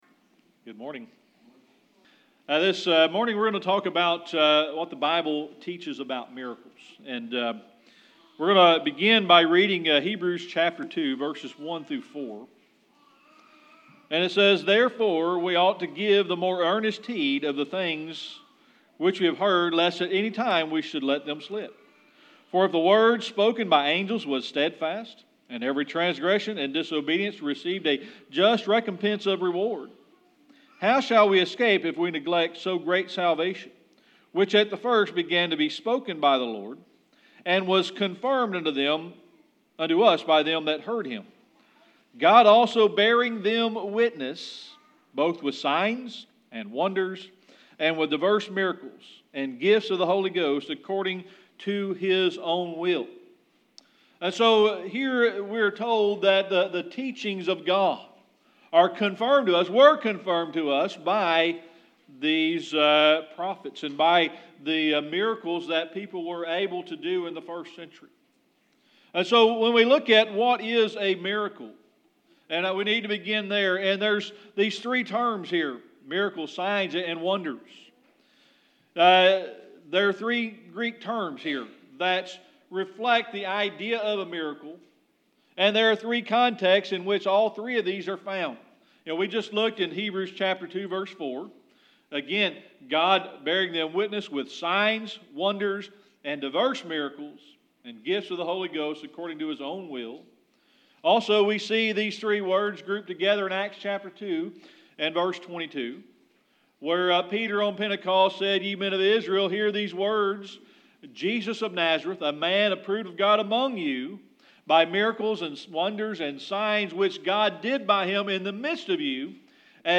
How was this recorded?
Hebrews Service Type: Sunday Morning Worship This morning we are going to be looking at what the Bible teaches about miracles.